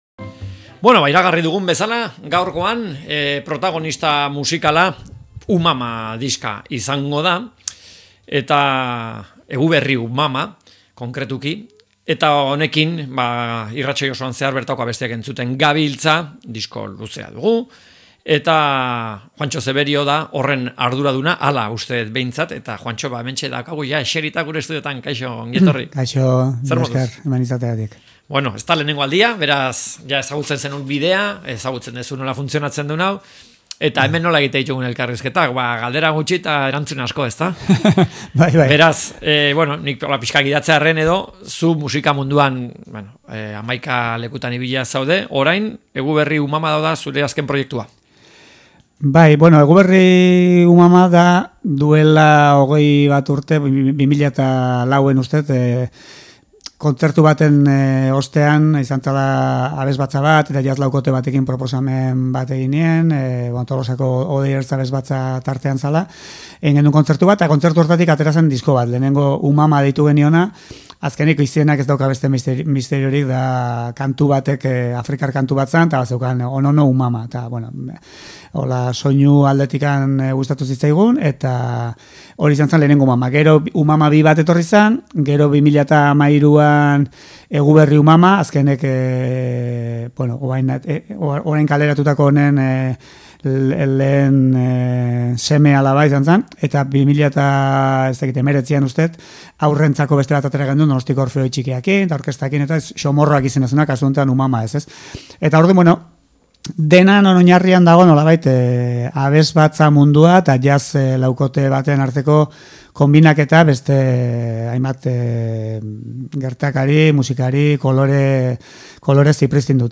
Elkarrizketak